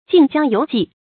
注音：ㄐㄧㄥˋ ㄐㄧㄤ ㄧㄡˊ ㄐㄧˋ
敬姜猶績的讀法